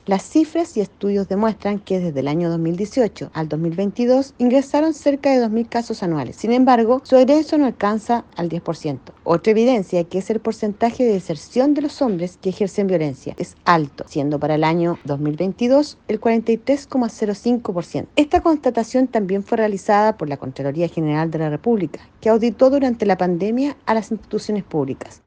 La directora regional de Sernameg Los Ríos, Waleska Ferhmann, detalló que dentro de las cifras de atención solo el 10% egresa y que el número de deserciones desde dichos espacios es alto.